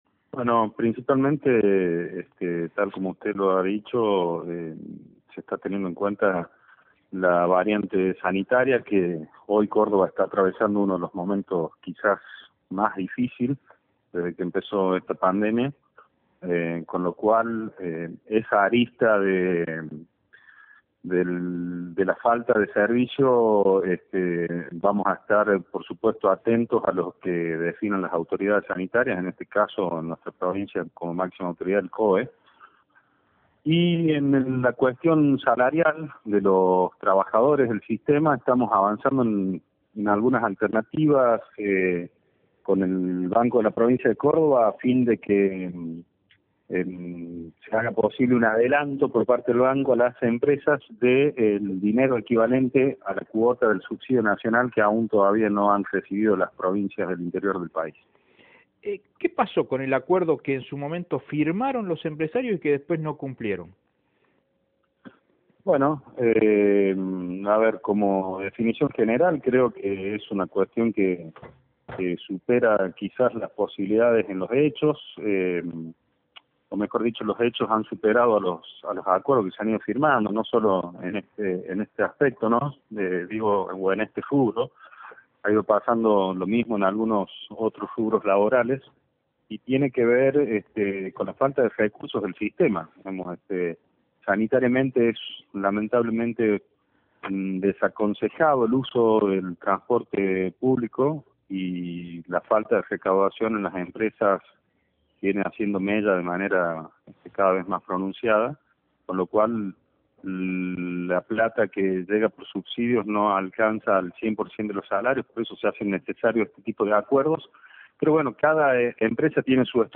Audio: Franco Mogetta (Secretario de Transporte – Pcia. Cba.).